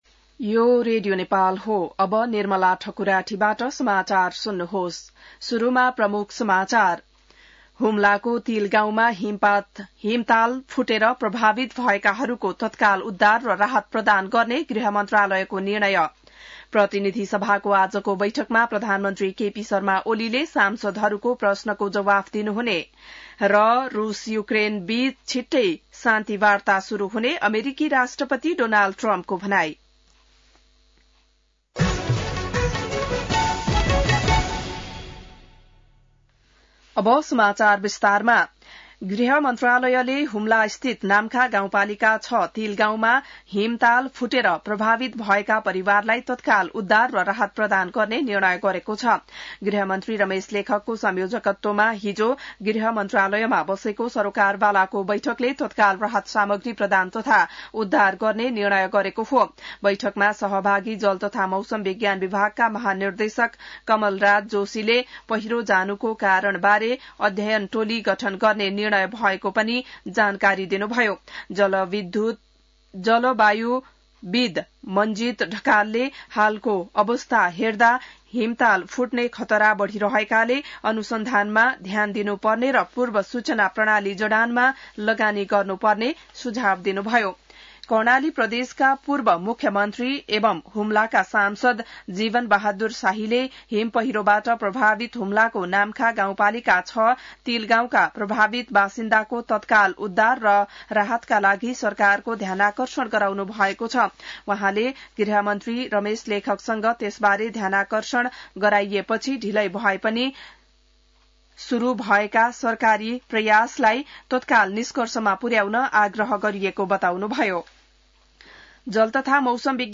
बिहान ९ बजेको नेपाली समाचार : ६ जेठ , २०८२